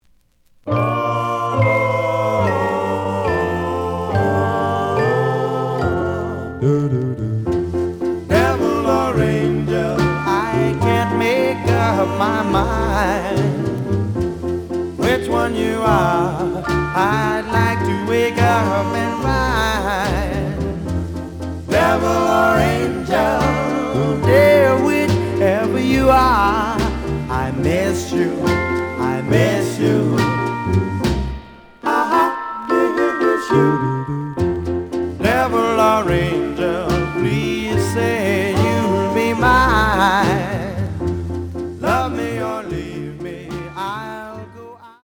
試聴は実際のレコードから録音しています。
The audio sample is recorded from the actual item.
●Format: 7 inch
●Genre: Rhythm And Blues / Rock 'n' Roll